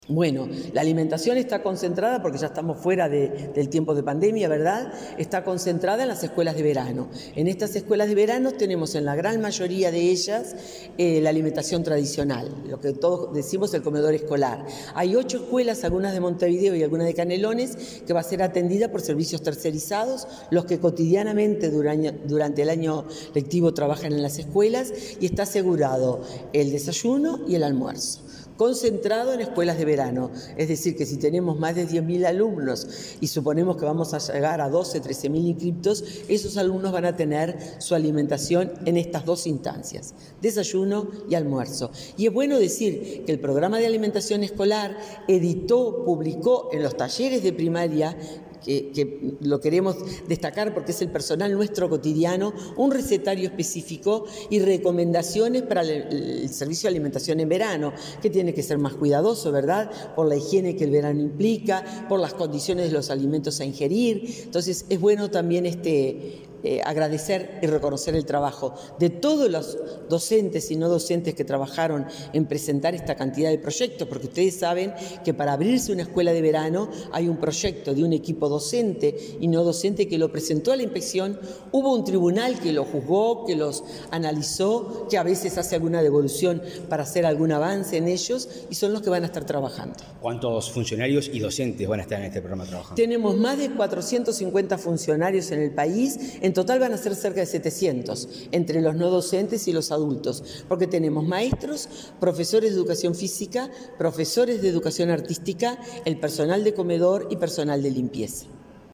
Entrevista a la directora general de Educación Inicial y Primaria, Graciela Fabeyro